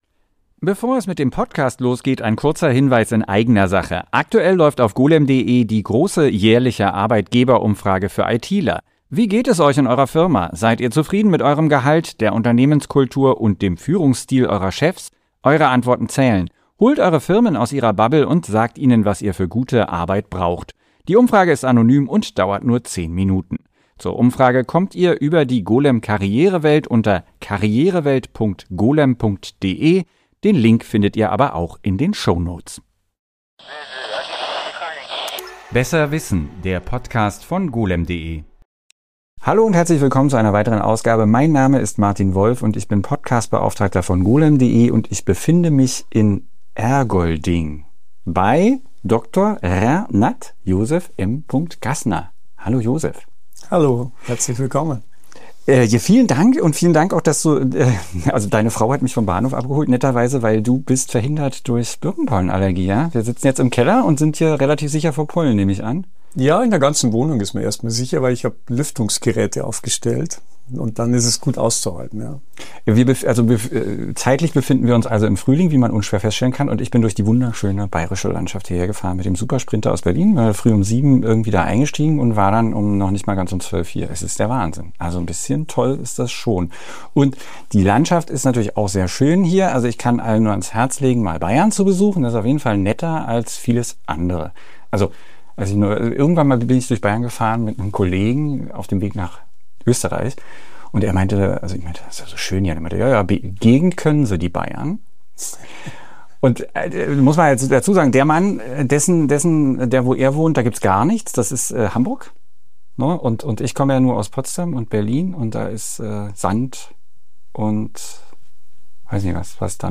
Mit unserem wöchentlichen Podcast Besser Wissen wollen wir unterhaltsam über Technologie- und Wissenschaftsthemen informieren. Wir reden mit der Golem-Redaktion und führen Interviews mit externen Expertinnen und Experten.